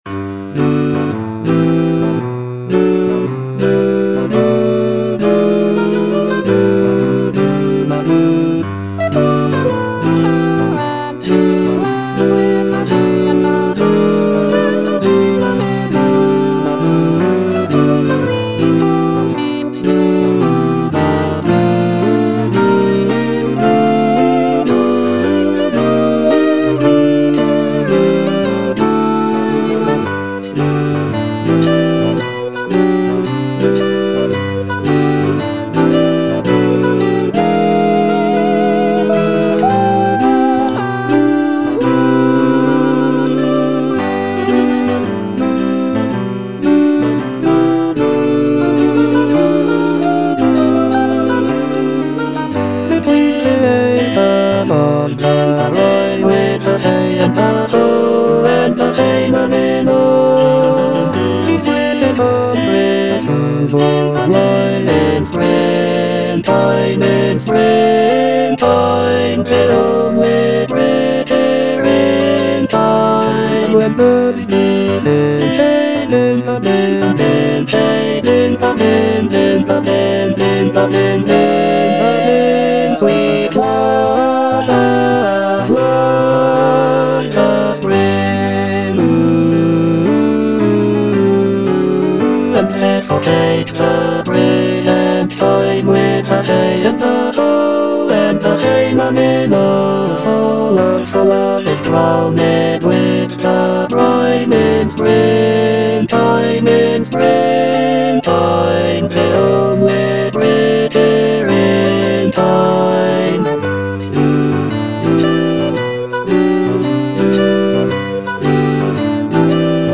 Baritone (Emphasised voice and other voices)